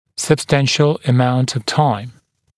[səb’stænʃl ə’maunt əv taɪm][сэб’стэншл э’маунт ов тайм]существенное количество времени, длительное время